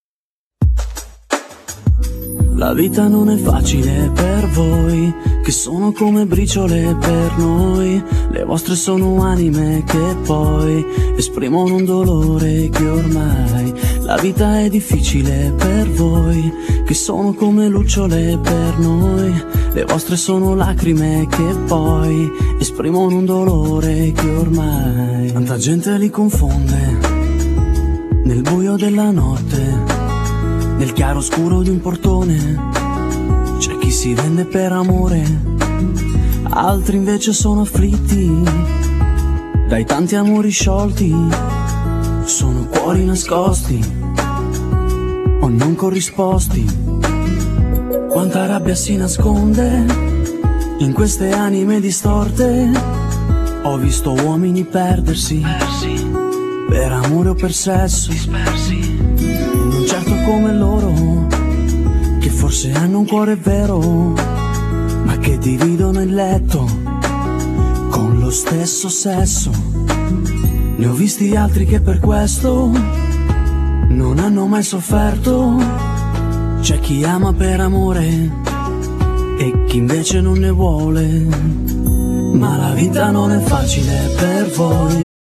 Genere : Pop Disco